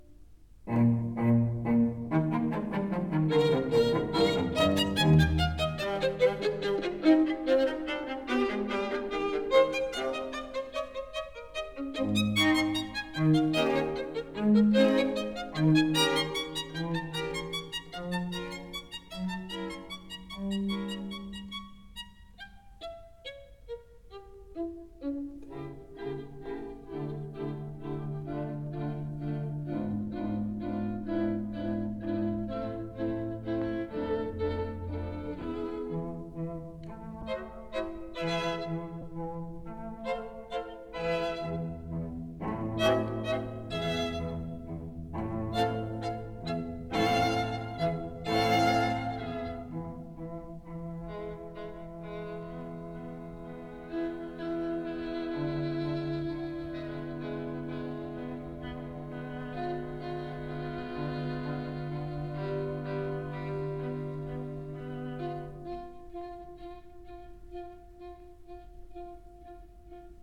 violin
viola
cello
Stereo recording made in Columbia
Studios,30th Street, New York City